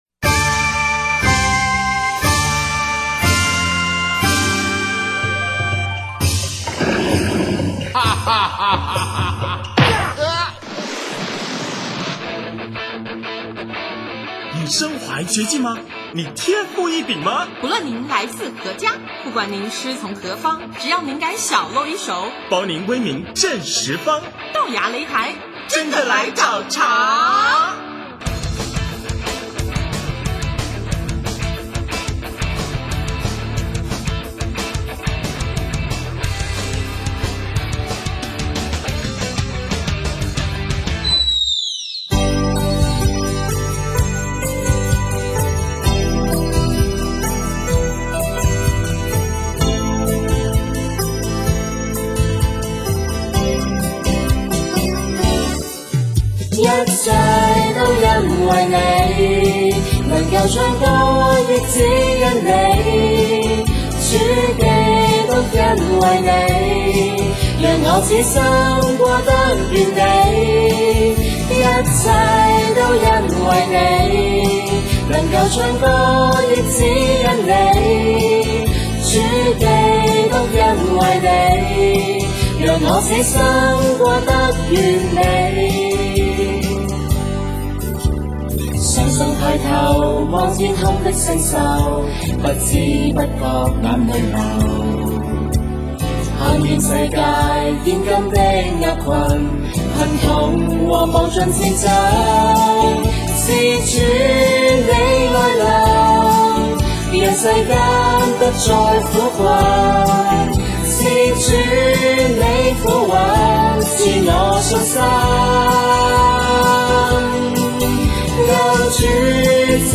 【豆芽擂台】143| 专访广州露德青年(四)：和天主一起走